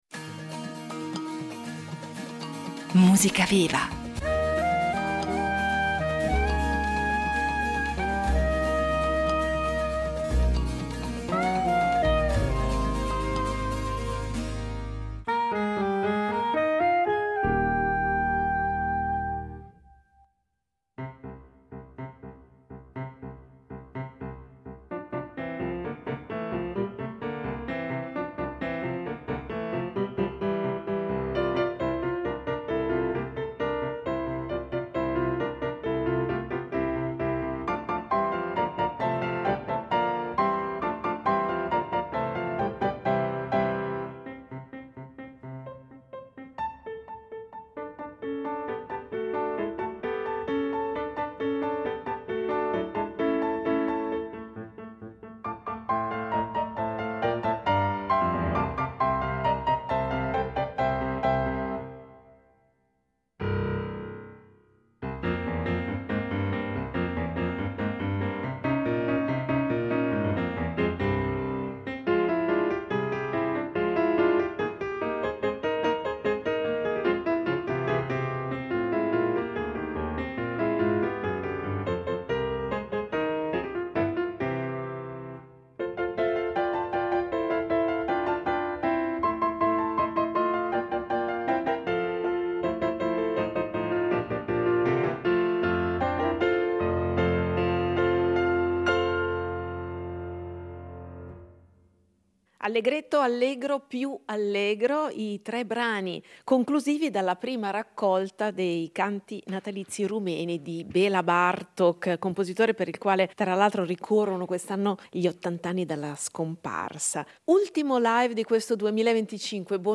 pianoforte
in diretta su Rete Due , nella più rara versione pianistica